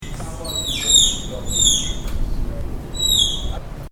На этой странице собраны разнообразные звуки тапиров — от нежного похрюкивания до громкого рычания.
Звуки тапира: Писк животного